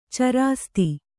♪ carāsti